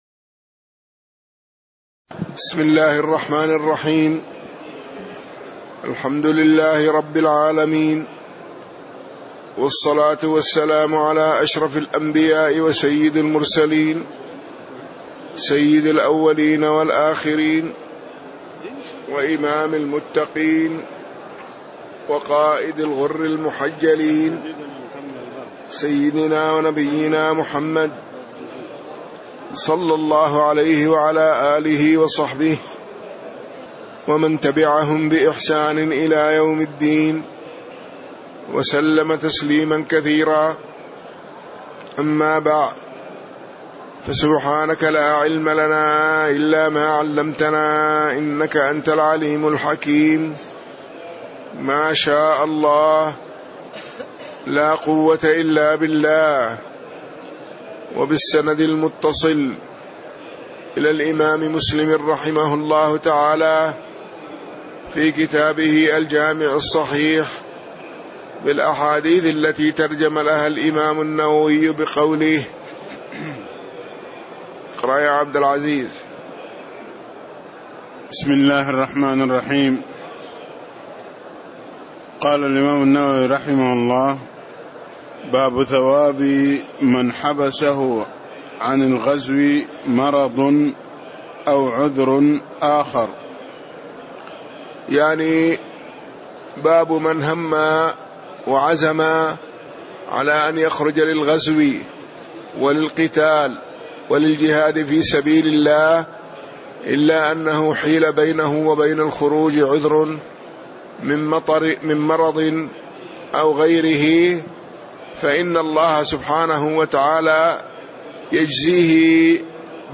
تاريخ النشر ١٥ ربيع الثاني ١٤٣٦ هـ المكان: المسجد النبوي الشيخ